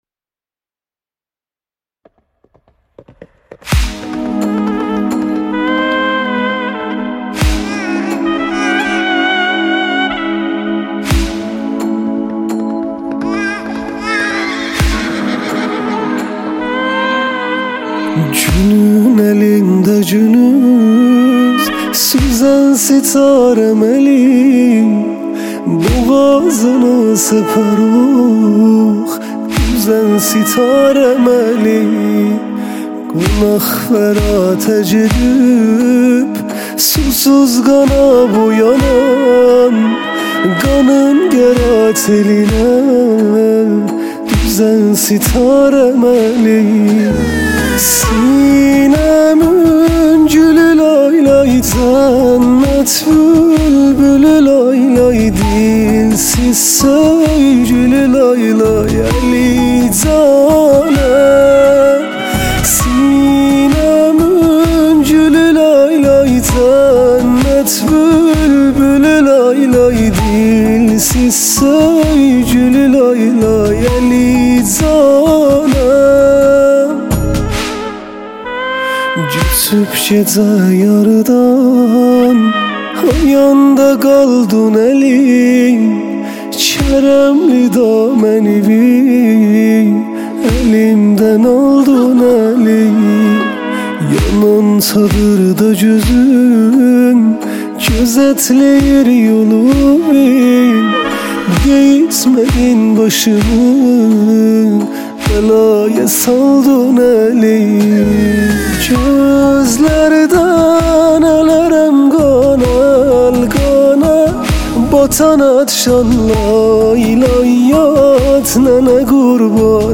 دانلود مداحی ترکی
نوحه و مداحی